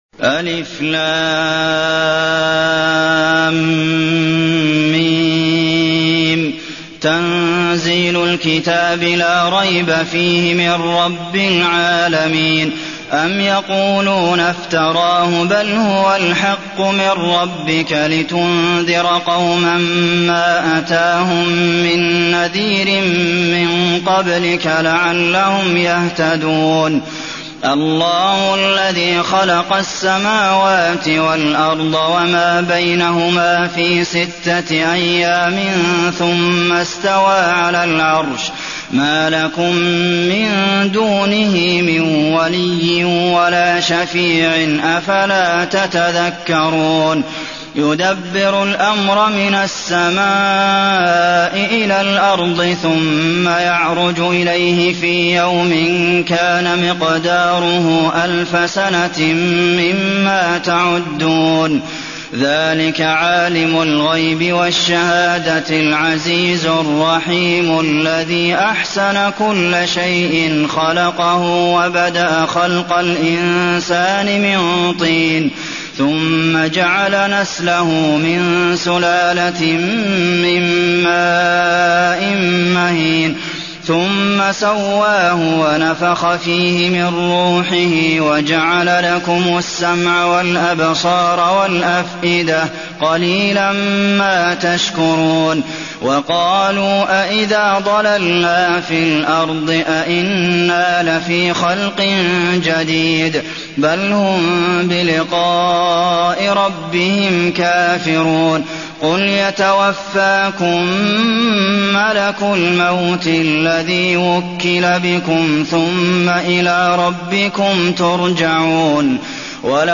المكان: المسجد النبوي السجدة